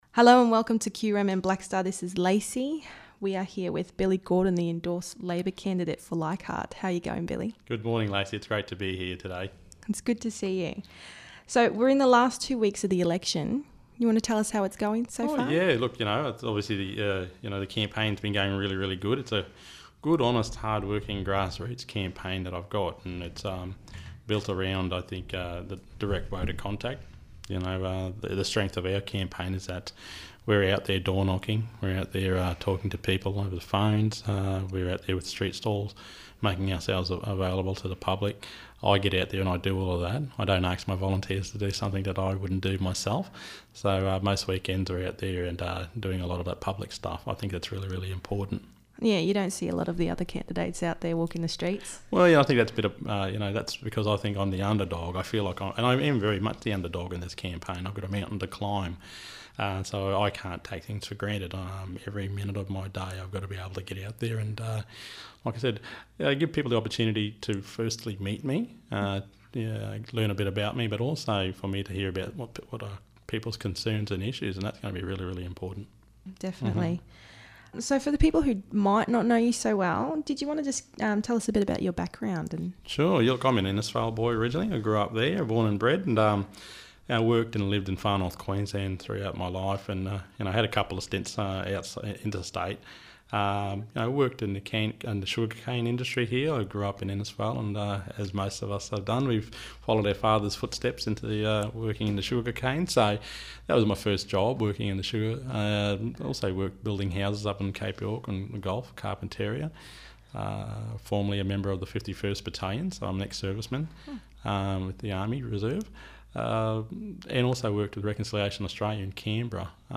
The election for the federal seat of Leichardt is hotting up, with the opposition LNP under pressure to release their policy costings. The endorsed Labor candidate for the seat, Billy Gordon, visited the Black Star studios and outlined the Labour party policies.
interview
billy-gordon-interview-full.mp3